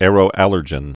(ârō-ălər-jən)